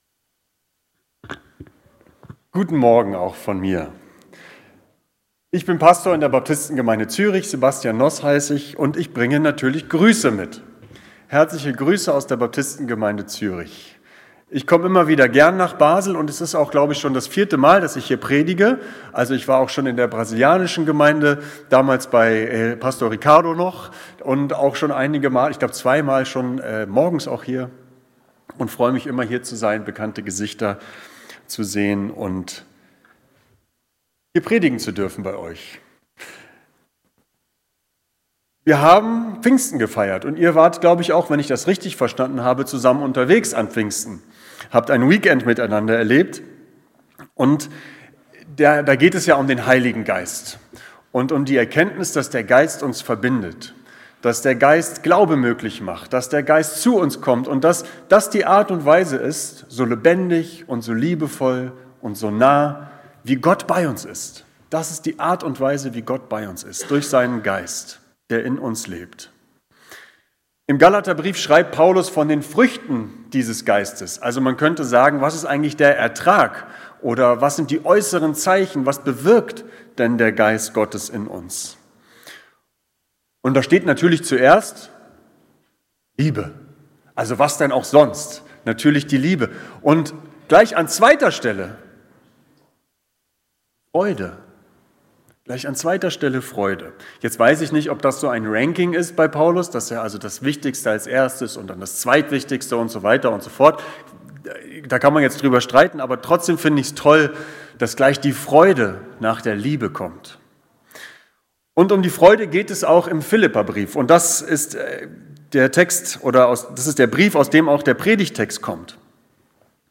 Predigten
Hier finden Sie die aktuellen Sonntagspredigten der Baptistengemeinde Basel.